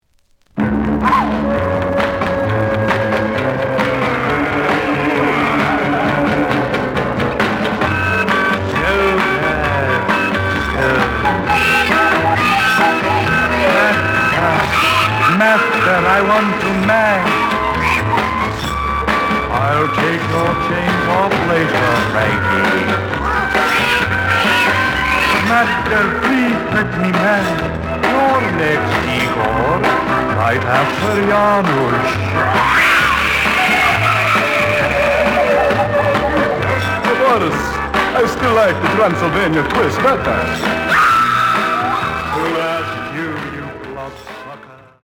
The audio sample is recorded from the actual item.
●Genre: Rock / Pop
Slight noise on B side.)